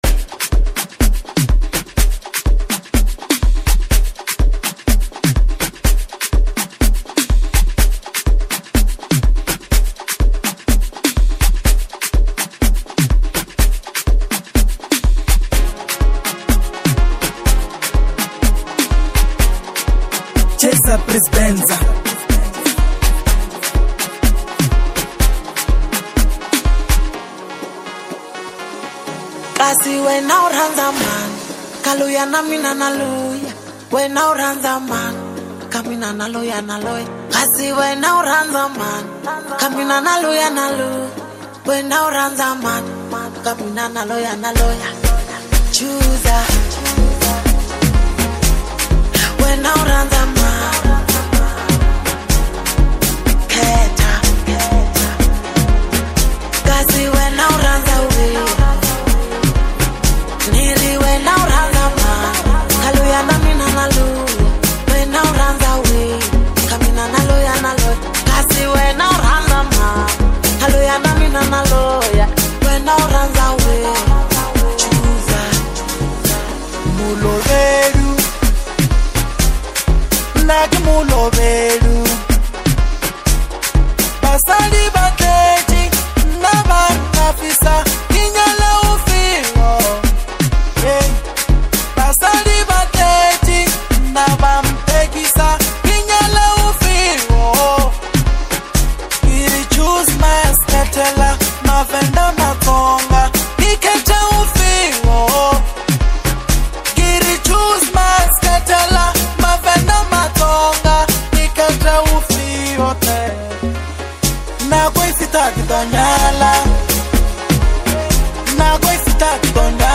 an energetic and vibrant track
powerful vocals